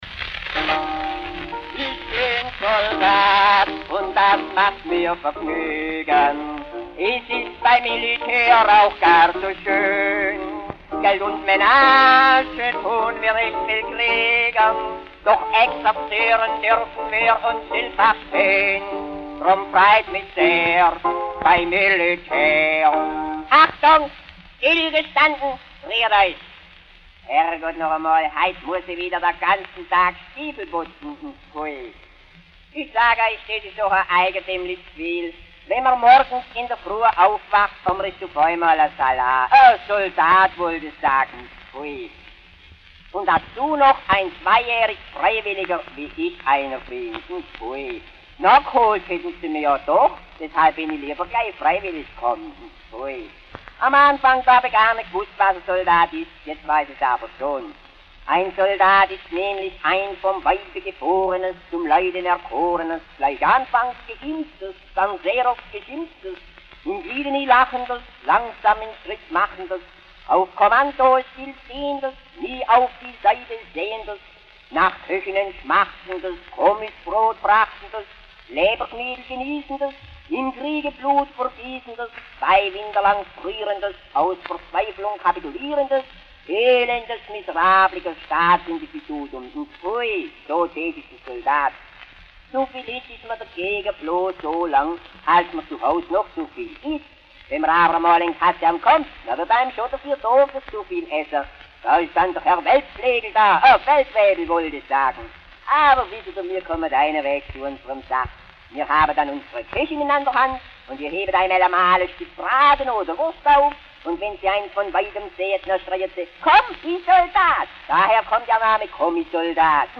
p> Volkskundearchiv Johan der Leutnantbursche Teil 1 Autor(en): Humorist
Quelle: Schellackplattensammlung Schw�bisches Kulturarchiv (o.J.)